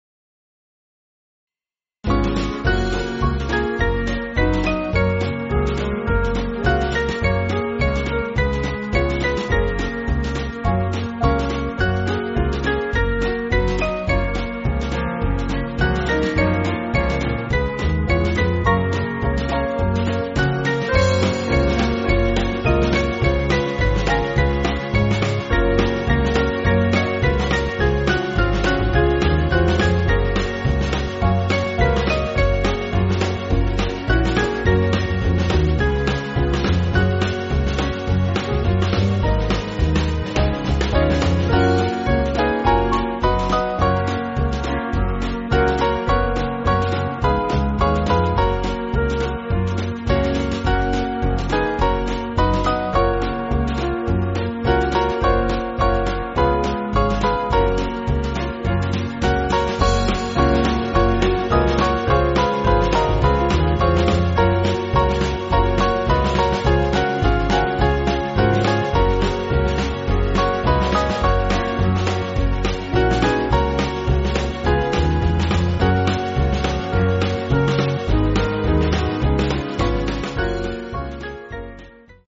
Small Band
(CM)   4/Gb-G